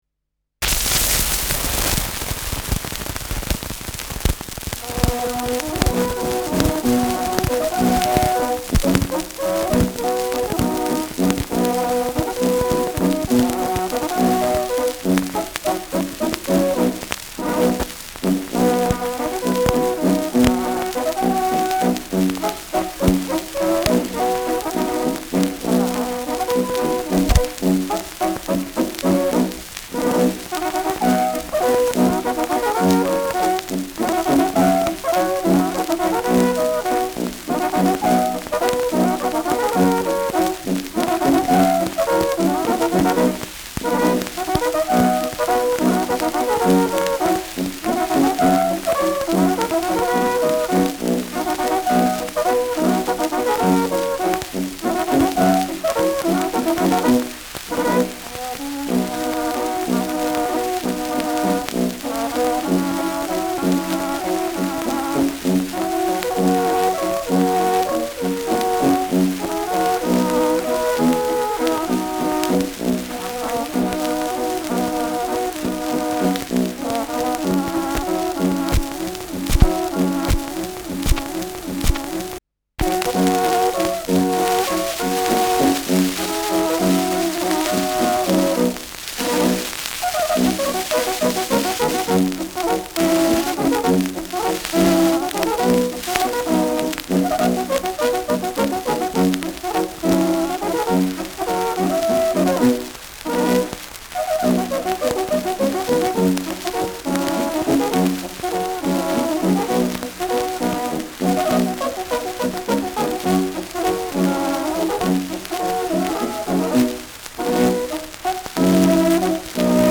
Schellackplatte
dumpfes Knacken zu Beginn und Mitte : präsentes Rauschen : Tonarm „rutscht“ über einige Rillen bei 0’27’’ : „Hängen“ bei 1’20’’ : präsentes Knistern
D’ Pernecker Innviertler Bauernmusik (Interpretation)